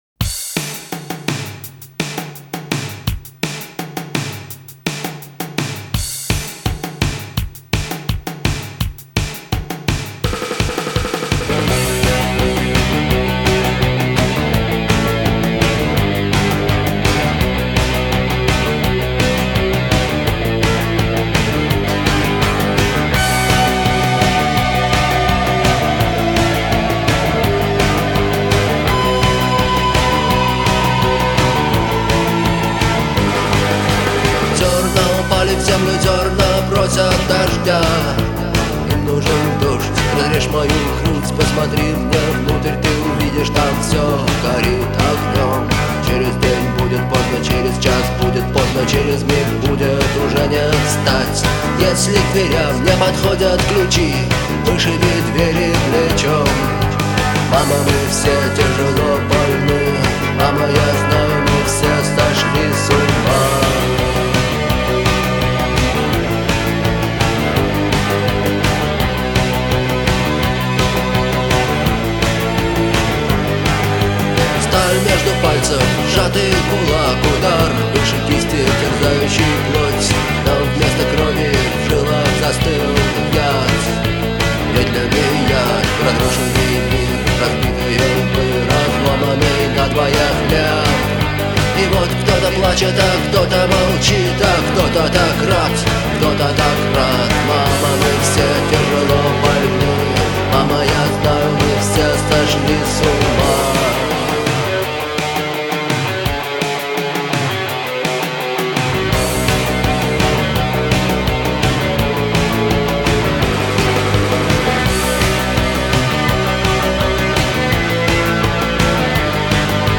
это культовая композиция в жанре рок